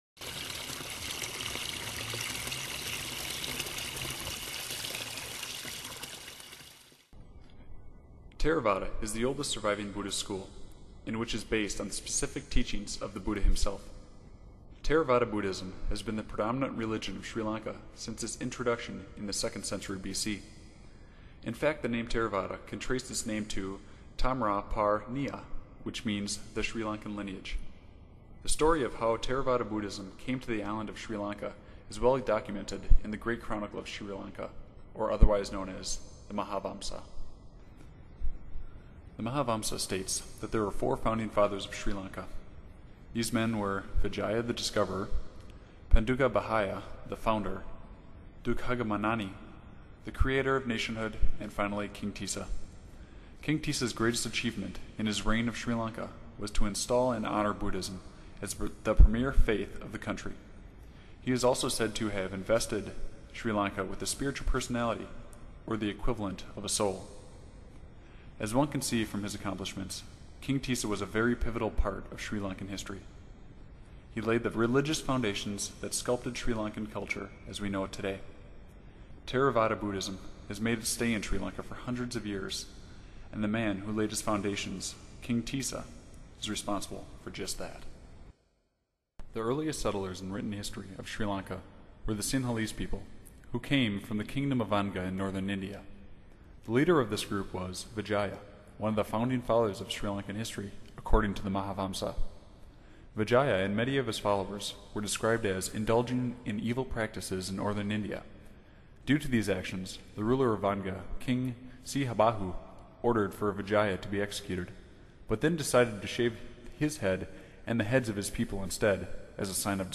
UKT 130626: When you listen to video-sound you will notice that the narrator's pronunciation of {tai�~�a.} is: /tiːs'sa/ .